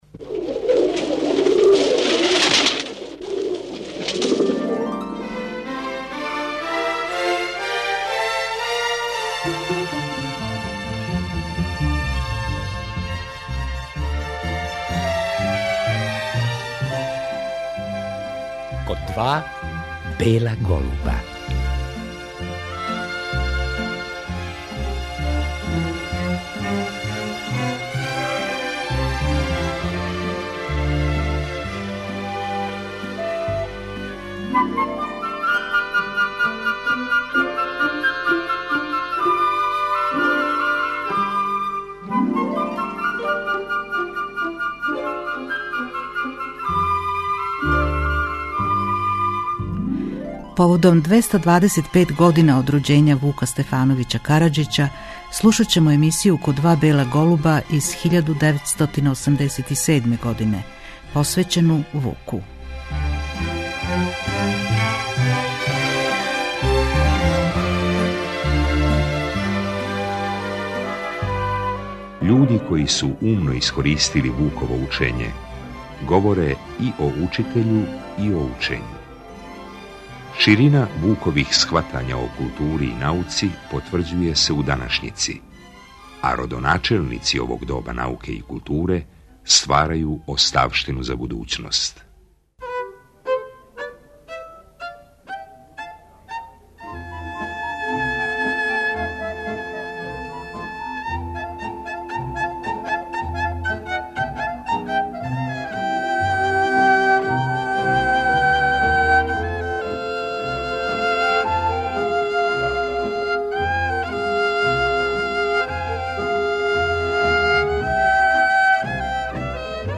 Тим поводом чућемо емисију 'Код два бела голуба' из 1987. године у којој су говорили људи који су умно искористили Вуково учење. Они су говорили и о учитељу и о учењу.